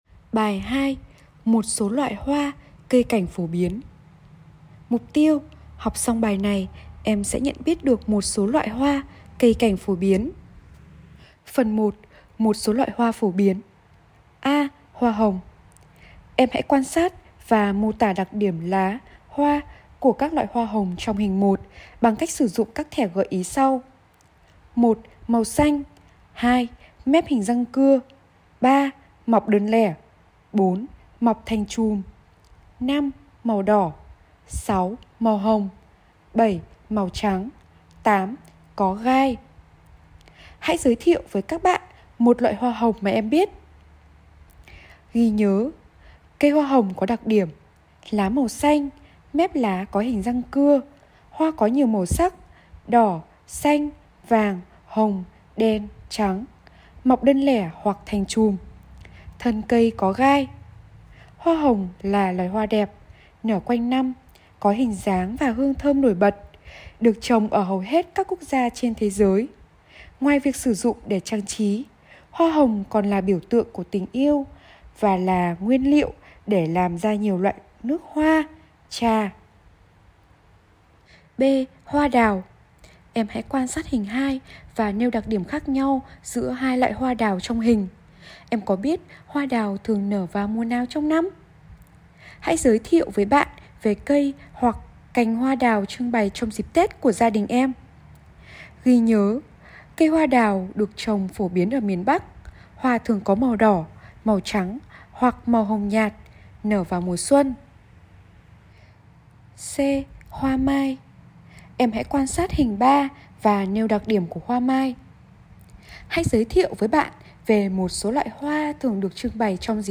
Sách nói | MỘT SỐ LOẠI HOA, CÂY CẢNH - CÔNG NGHỆ 4